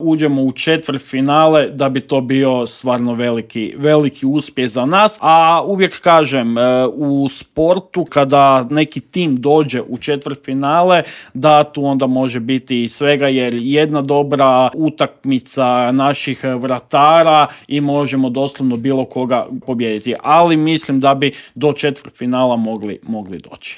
U intervjuu Media Servisa razgovarali smo o "maloj tvornici medaljaša"